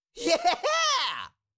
Cartoon Little Monster, Voice, Yeah 3 Sound Effect Download | Gfx Sounds
Cartoon-little-monster-voice-yeah-3.mp3